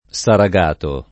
[ S ara g# to ]